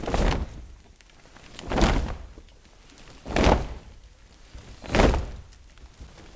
STOMPS    -L.wav